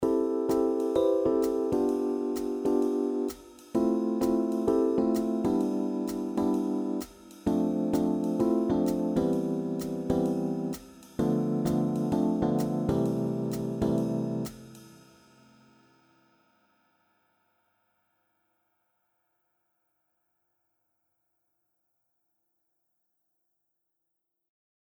Inzingoefening met secundes tussen sopraan en alt
Hier is een vergelijkbare oefening waarbij er tussen de sopraan en alt telkens één toon ligt:
Secunde-tussen-sopraan-en-alt.mp3